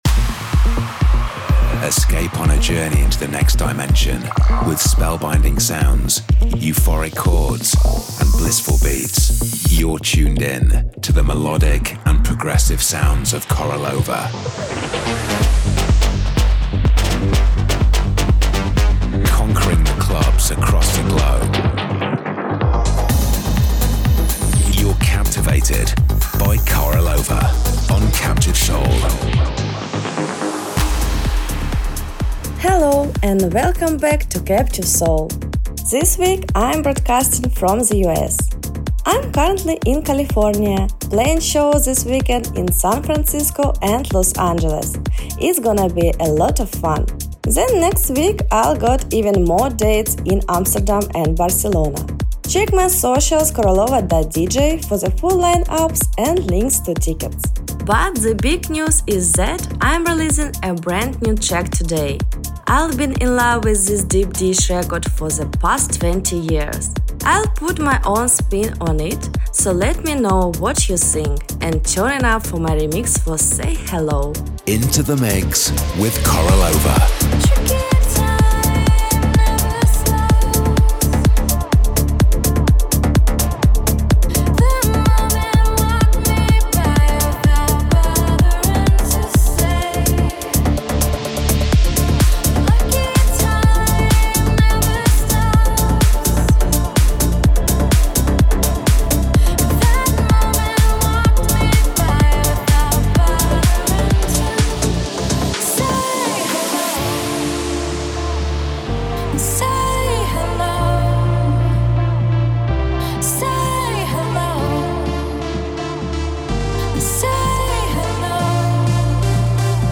The bi-weekly radio show